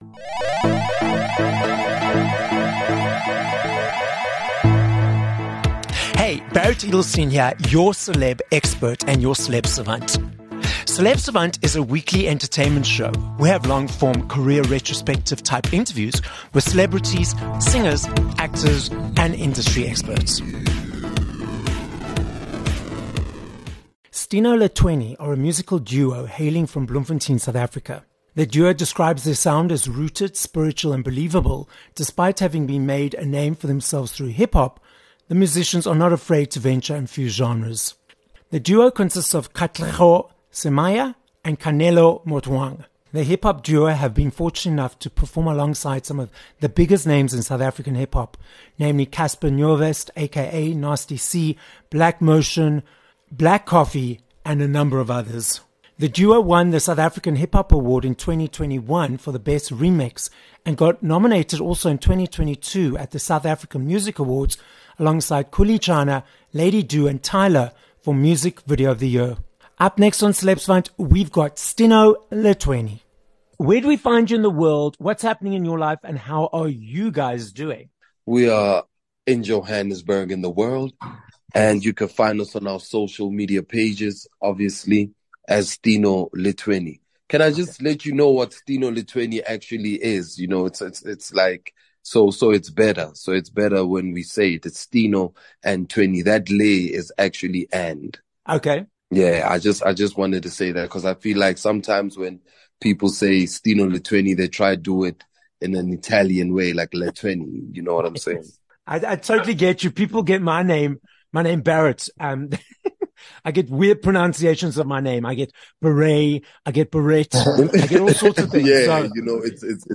24 Aug Interview with Stino Le Thwenny